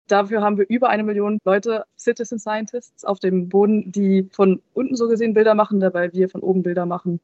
Dort, in einer Höhe von rund 400 Kilometern, sind wissenschaftliche Experimente geplant – zum Beispiel mit Polarlichtern, erklärt Rabea Rogge: